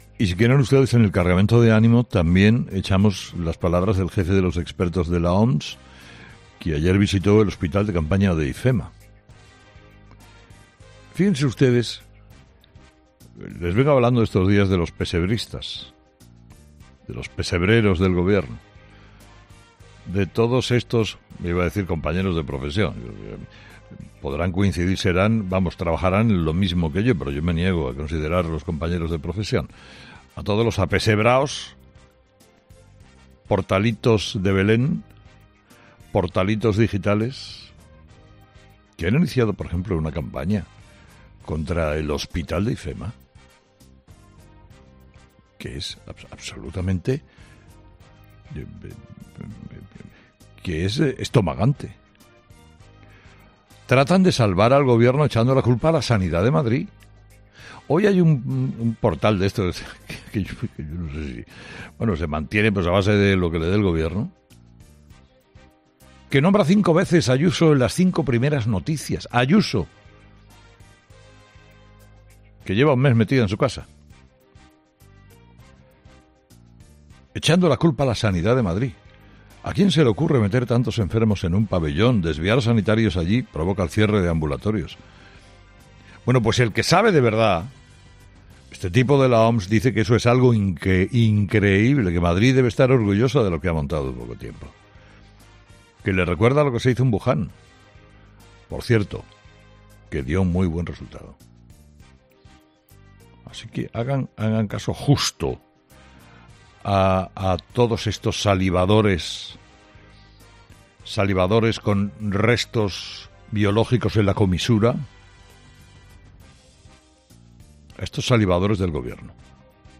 Carlos Herrera ha cargado este miércoles en su monólogo de las seis de la mañana contra los "pesebristas" del Gobierno.